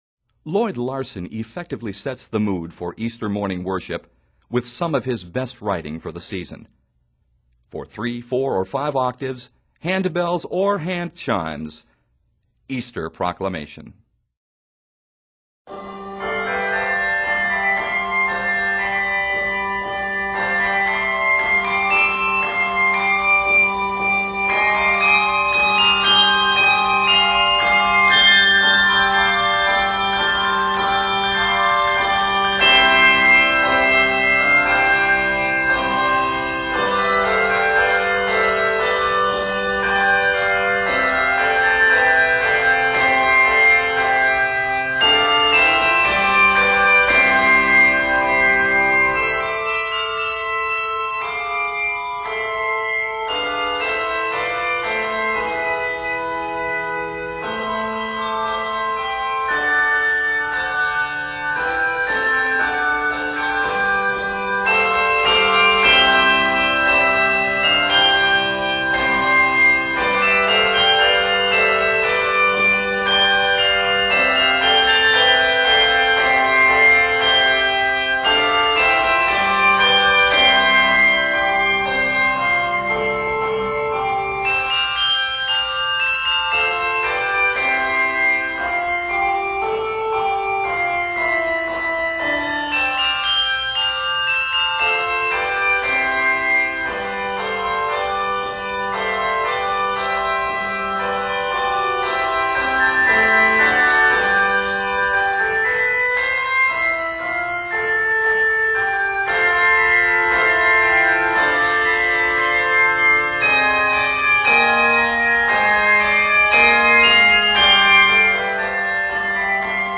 Arranged in C Major, measures total 76.